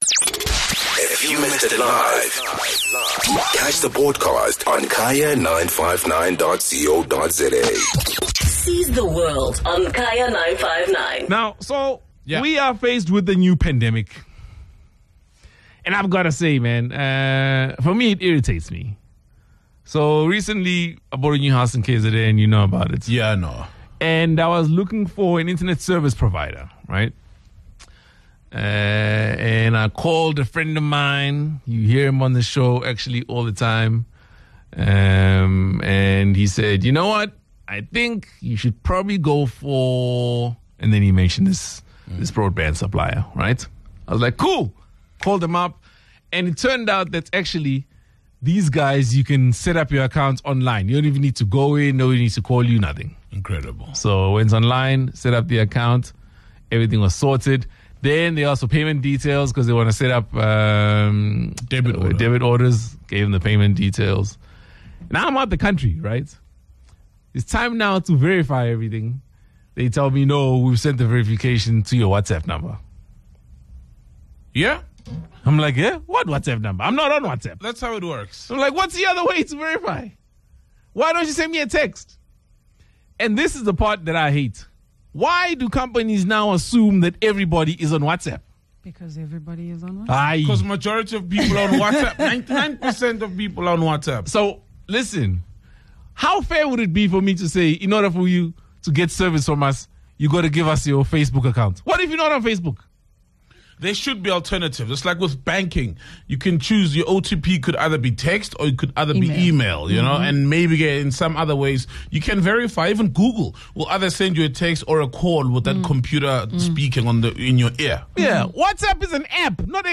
The team share their thoughts on the app being used for business operations.